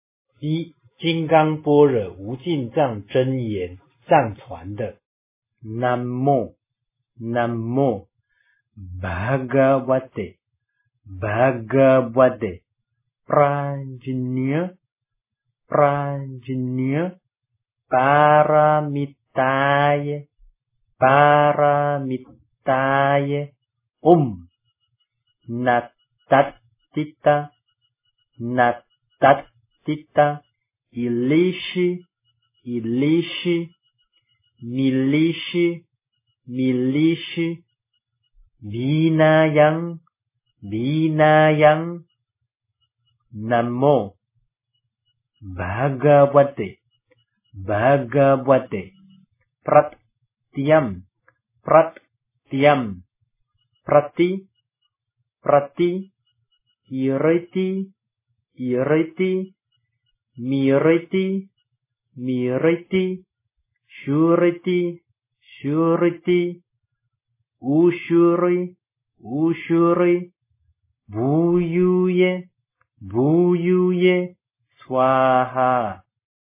金刚经的梵音咒语 - 诵经 - 云佛论坛